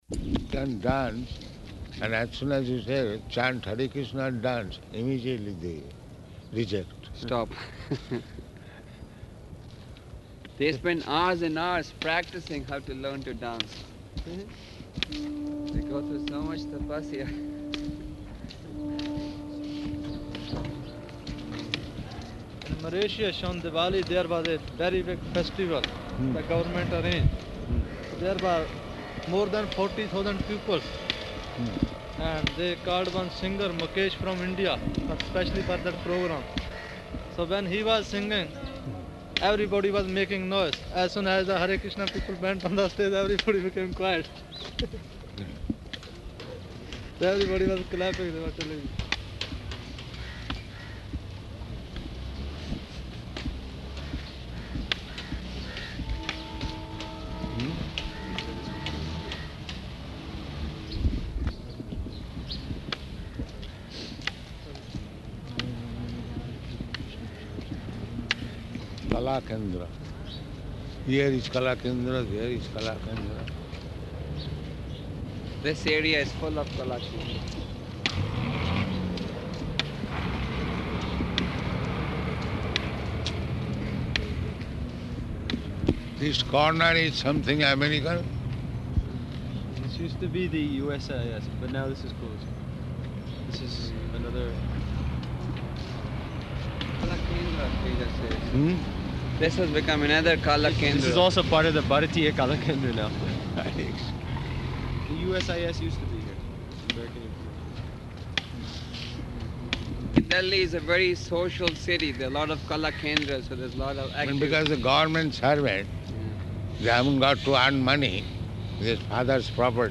Type: Walk
November 30th 1975 Location: Delhi Audio file